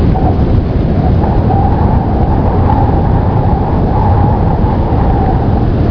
Vietor piskanie - dokola.wav